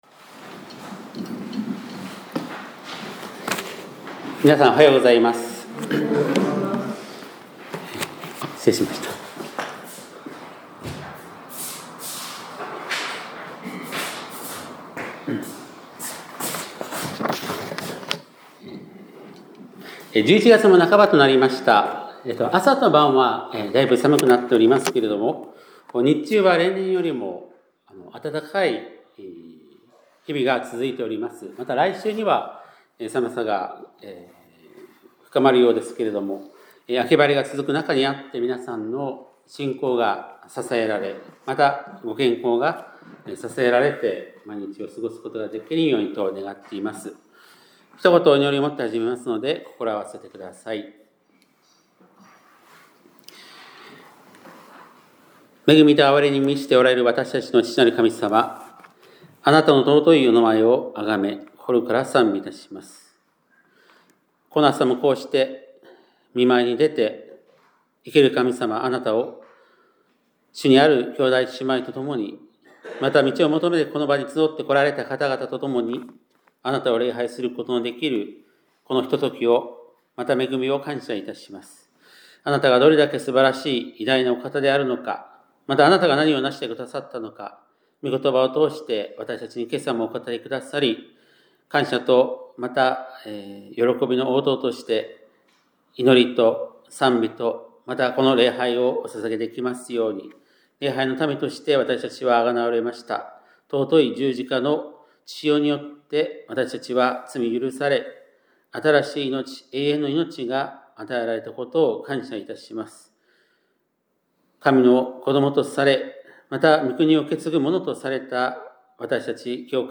2025年11月16日（日）礼拝メッセージ